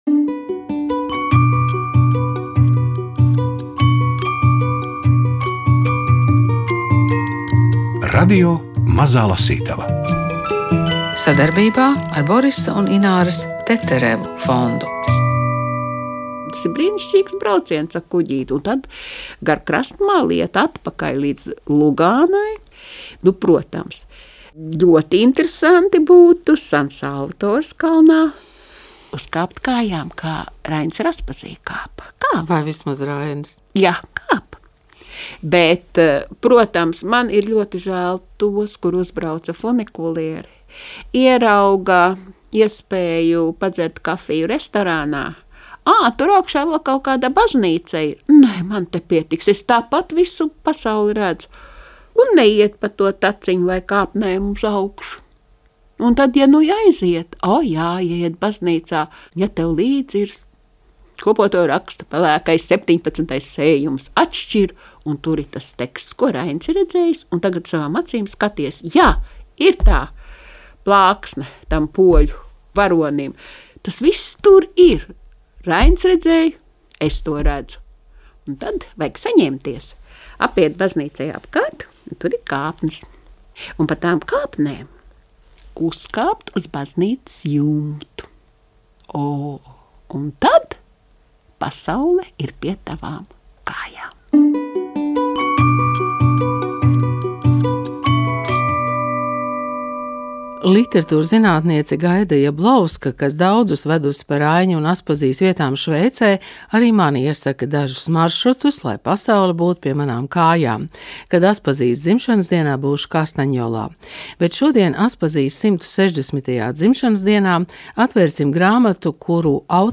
Mēs aicināt lasīt un tikties „Radio mazajā lasītavā”, kad jaunāko grāmatu fragmentus priekšā lasa aktieris Gundars Āboliņš. Studijā